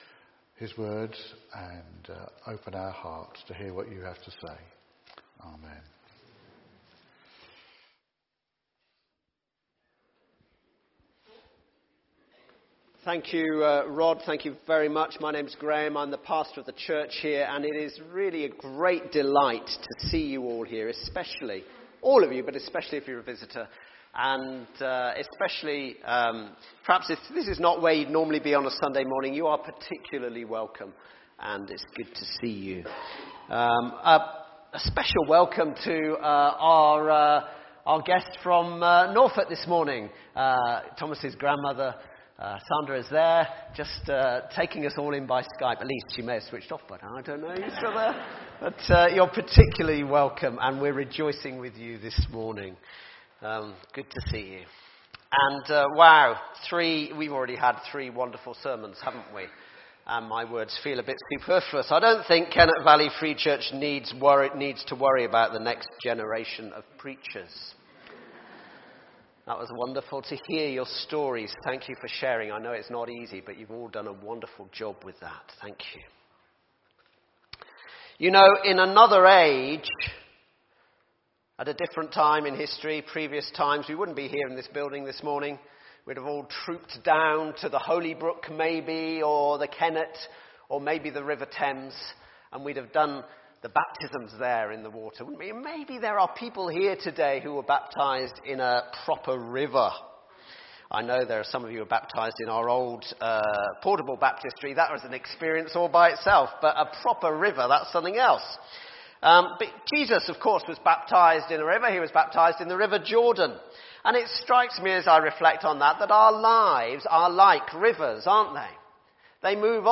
Baptismal Service – April 2018